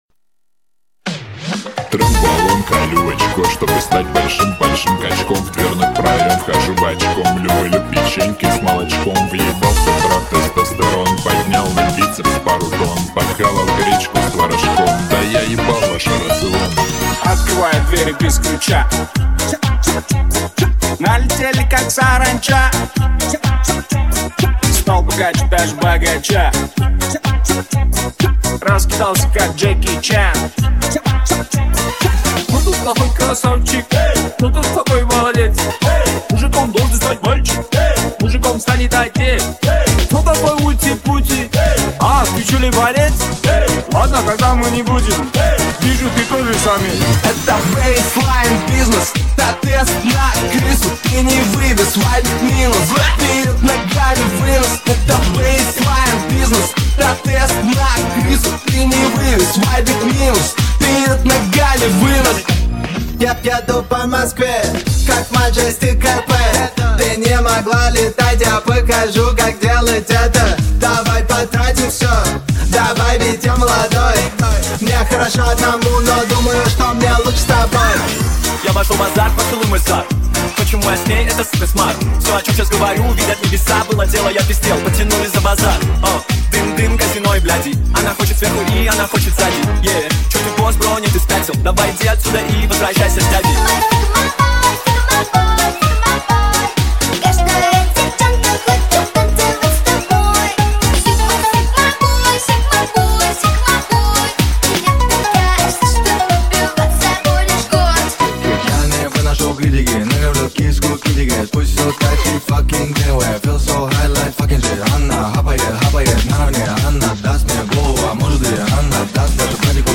mashup TikTok Remix